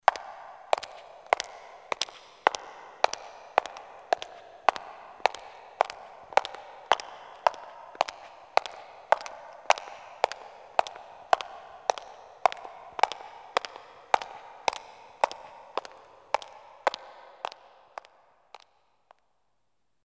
WAV · 3.4 MB · 立體聲 (2ch)